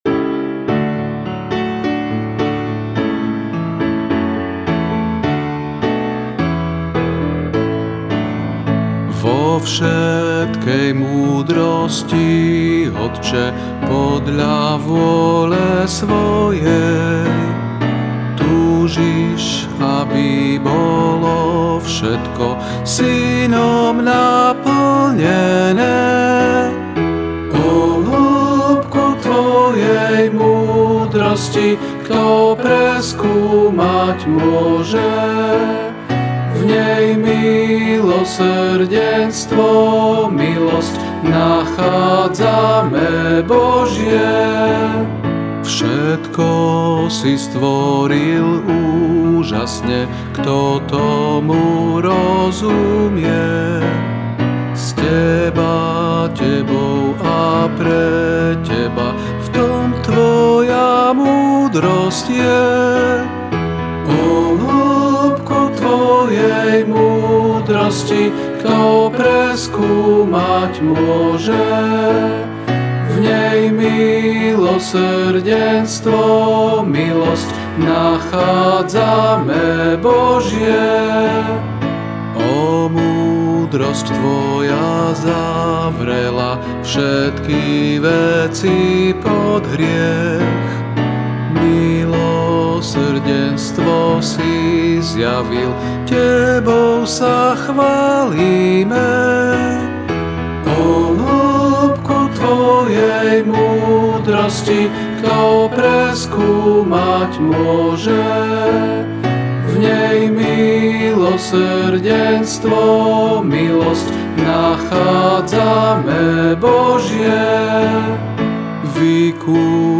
降B大調